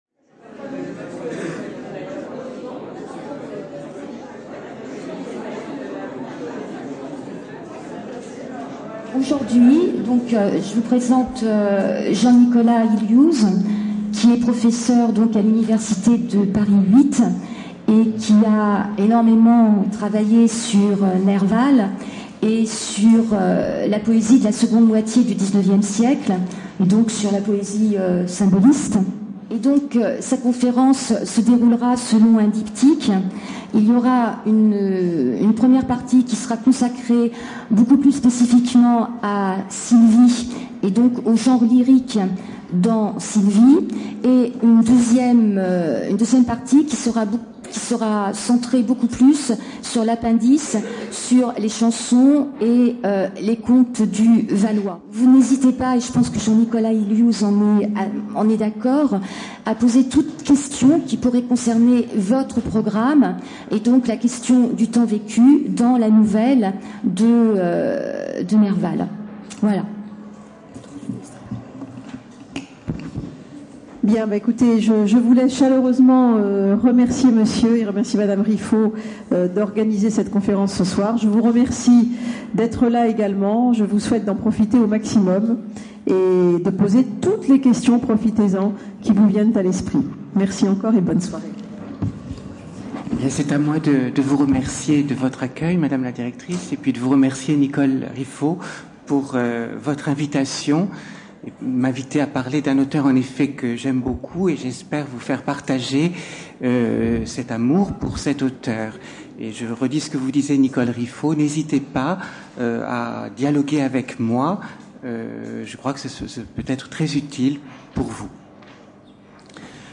Conférence n°1 : Sylvie de Nerval et les genres lyriques : l’idylle, l’élégie, la satire. À partir des catégories de Schiller, distinguant la « poésie naïve » et la « poésie sentimentale », nous montrerons comment le récit de Sylvie, en faisant jouer l’opposition de l’idéal et de la réalité, relève d’une poétique hybride, associant les trois genres lyriques fondamentaux que sont, selon Schiller, l’idylle (représentée par Sylvie), l’élégie (représentée par Adrienne), et la satire (portée par la voix narrative). Conférence n°2 : Autour des Chansons et légendes du Valois de Nerval.